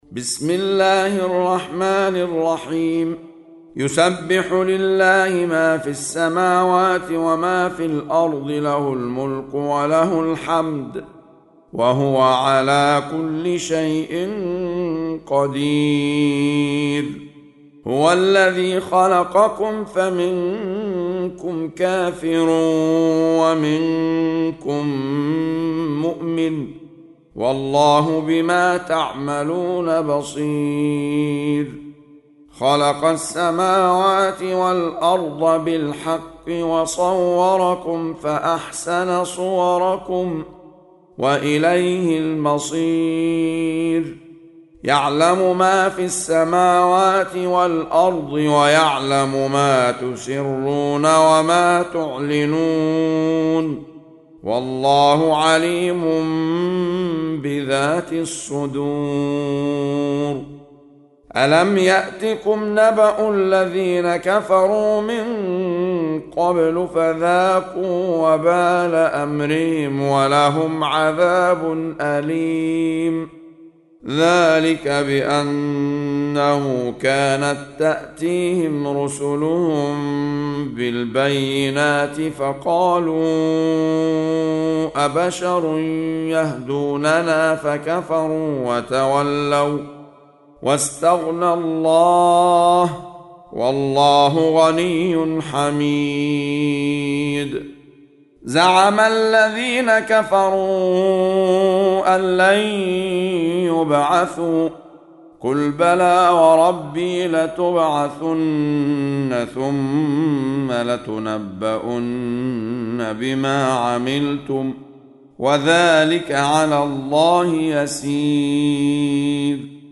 سورة التغابن | القارئ أحمد عيسي المعصراوي
سورة التغابن مدنية عدد الآيات:18 مكتوبة بخط عثماني كبير واضح من المصحف الشريف مع التفسير والتلاوة بصوت مشاهير القراء من موقع القرآن الكريم إسلام أون لاين